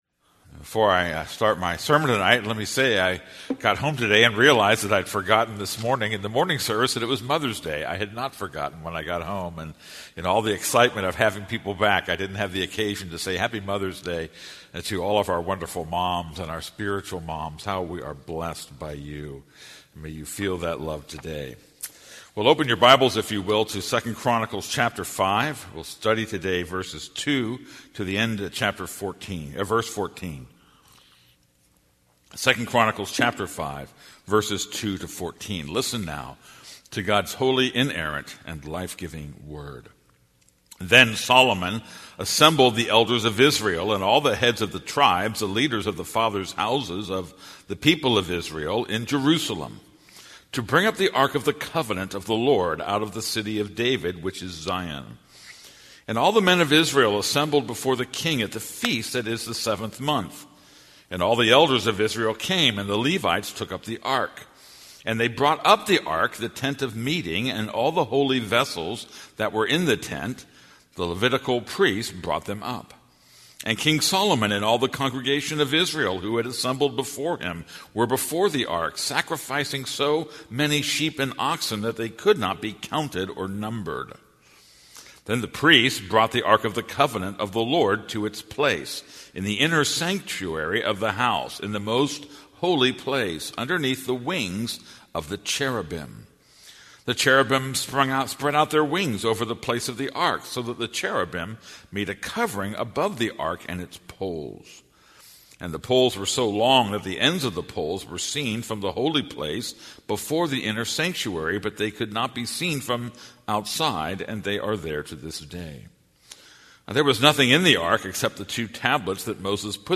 This is a sermon on 2 Chronicles 5:2-14.